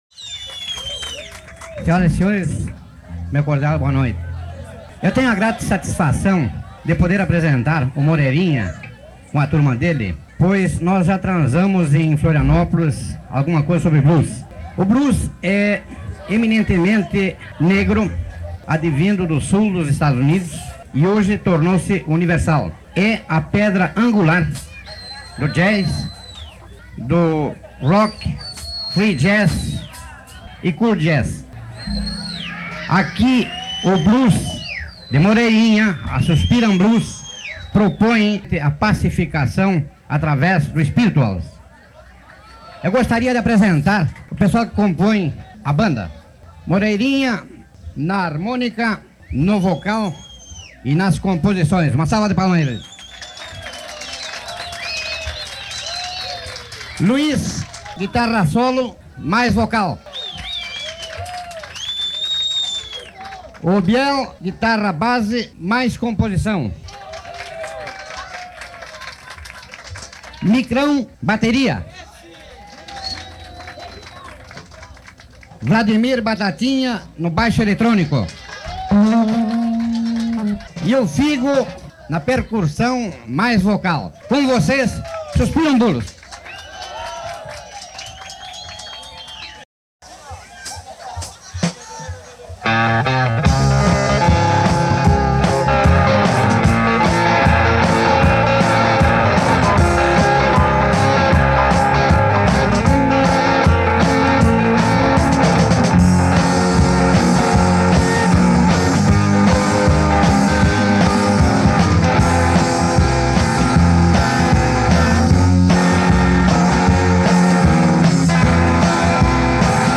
Nestas gravações ao vivo, a banda é formada por:
São dois shows distintos, em momentos totalmente diferentes.